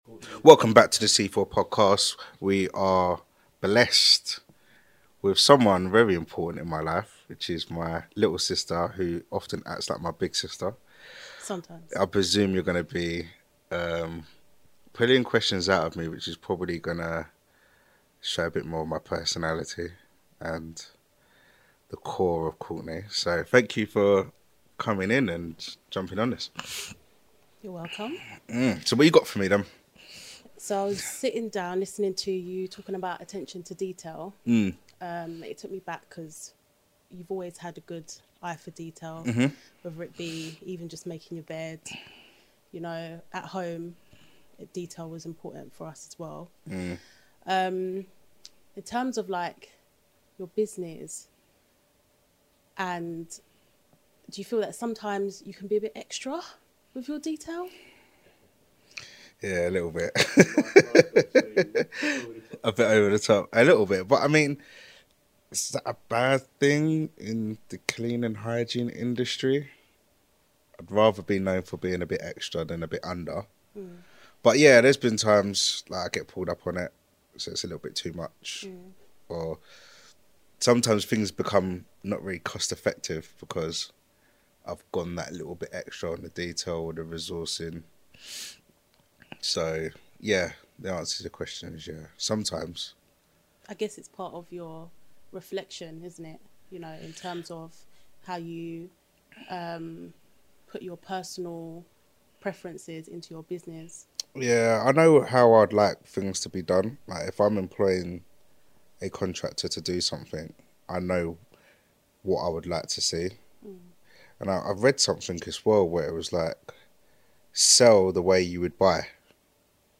Their candid conversation aims to inspire transparency and grit in personal and professional growth.